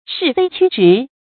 是非曲直 注音： ㄕㄧˋ ㄈㄟ ㄑㄩ ㄓㄧˊ 讀音讀法： 意思解釋： 曲：無理；直：有理。